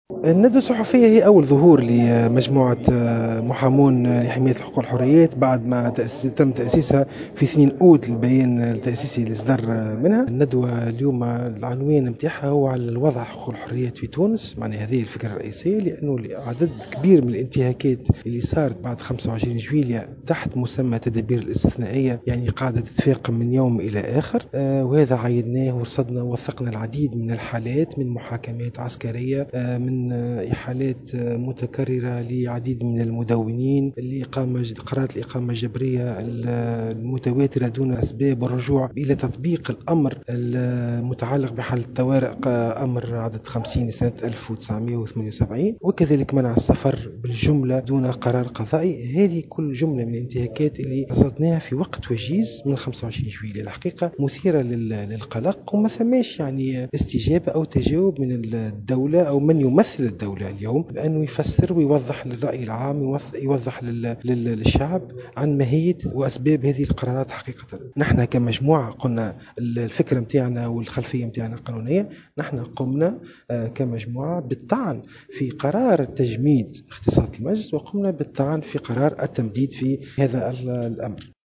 واوضح في تصريح لموفدة "الجوهرة أف أم" على هامش ندوة عقدتها المجموعة بتونس العاصمة، أن هذه الانتهاكات تتمثل في محاكمات عسكريّة وإحالات لمدوّنين وقرارات الإقامة الجبرية لعدد من الأشخاص دون أسباب واضحة، إضافة إلى إجراءات منع السفر والرجوع إلى تطبيق الأمر المتعلّق بحالة الطوارئ.